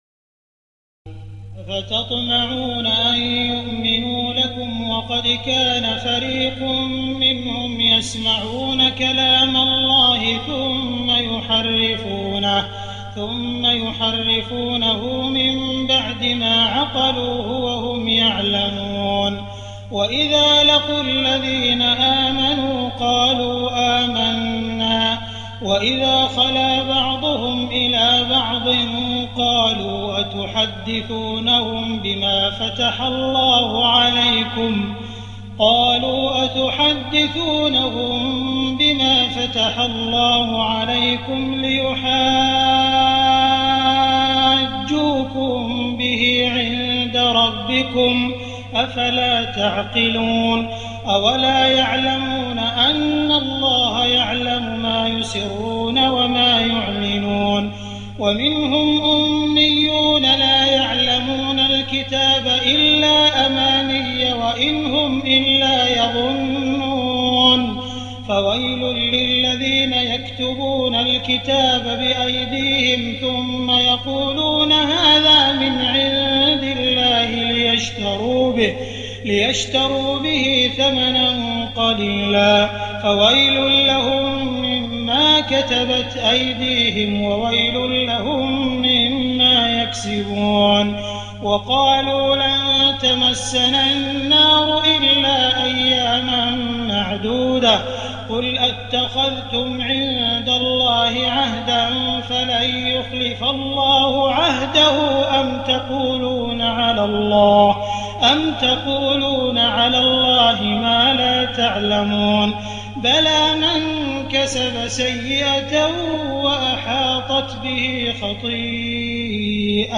تراويح الليلة الأولى رمضان 1419هـ من سورة البقرة (75-141) Taraweeh 1st night Ramadan 1419H from Surah Al-Baqara > تراويح الحرم المكي عام 1419 🕋 > التراويح - تلاوات الحرمين